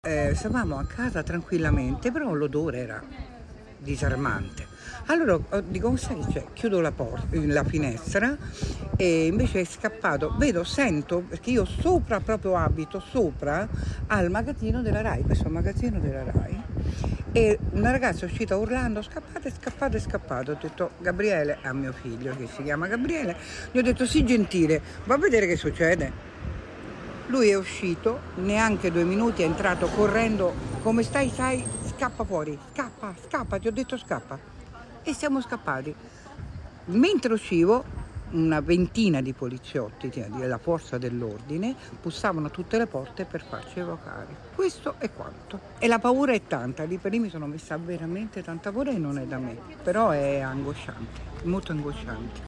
Ecco alcune testimonianze
TESTIMONIANZA-2-19.30-INCENDIO-ROMA.mp3